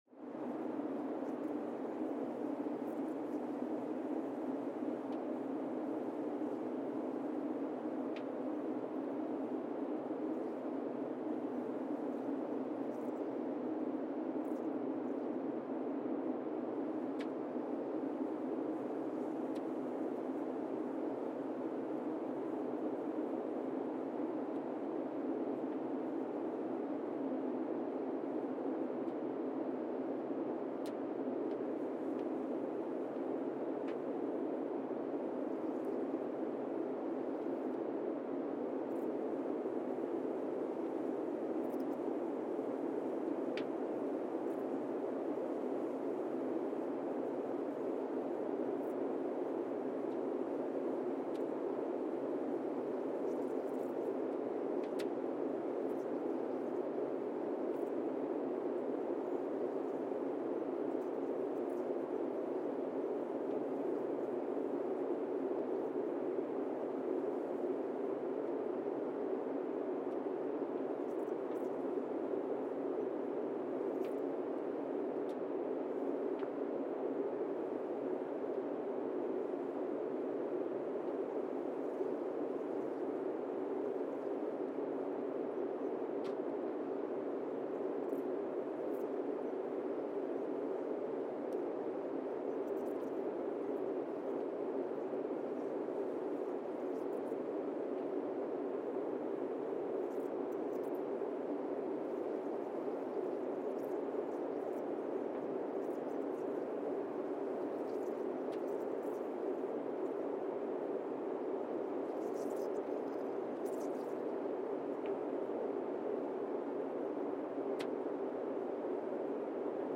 Monasavu, Fiji (seismic) archived on August 17, 2024
Sensor : Teledyne Geotech KS-54000 borehole 3 component system
Speedup : ×1,800 (transposed up about 11 octaves)
SoX post-processing : highpass -2 90 highpass -2 90